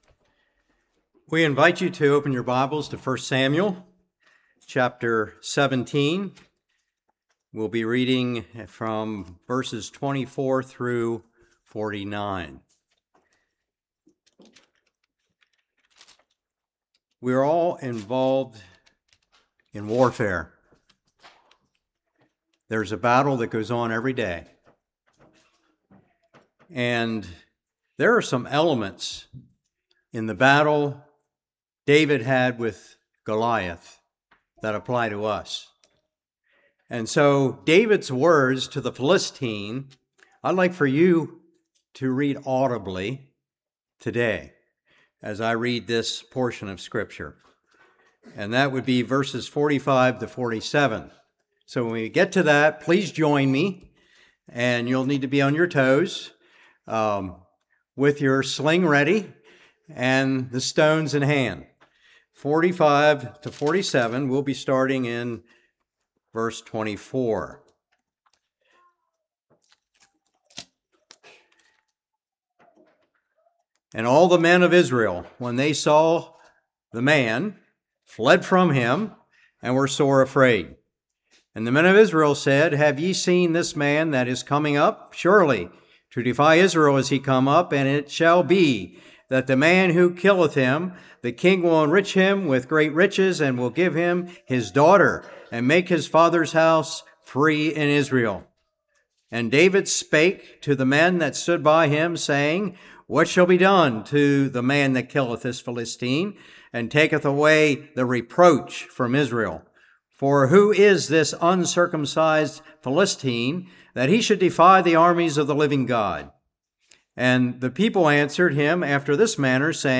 1 Samuel 17:24-49 Service Type: Morning Do we need to put down time consuming hobbies?